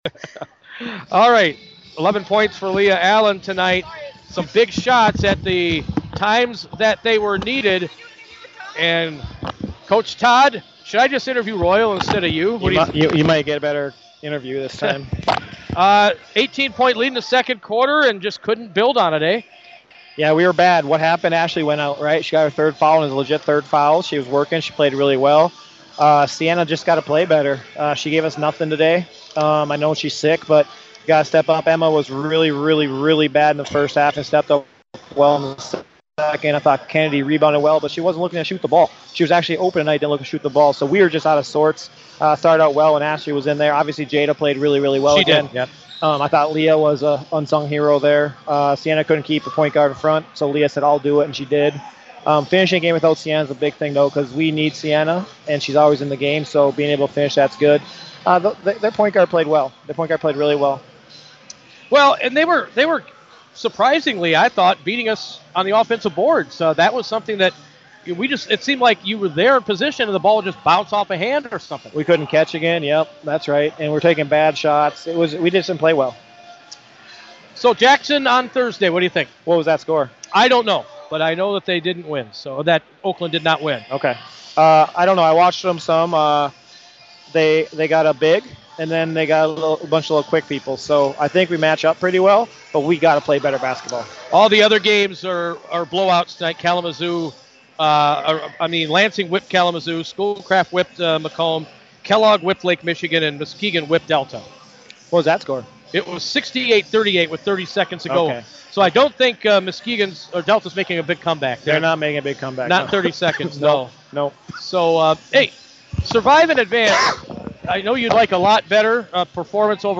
player spotlight interview